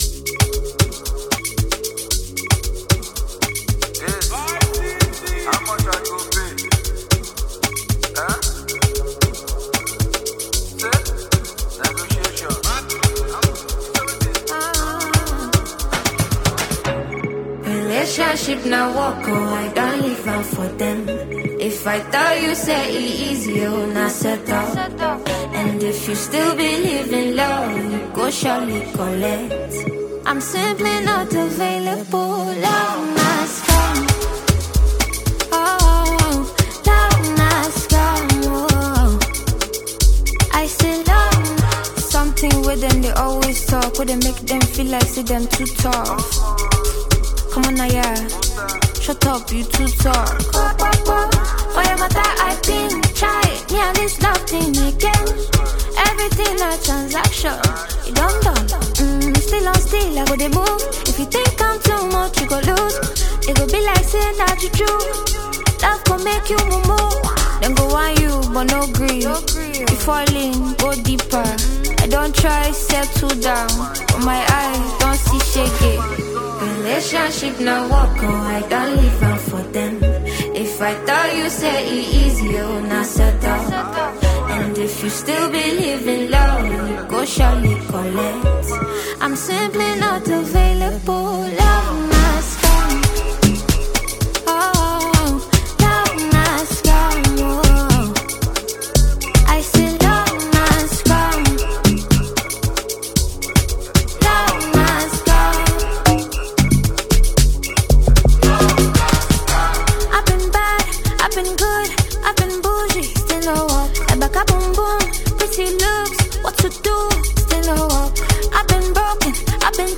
Known for her soulful voice and powerful lyrics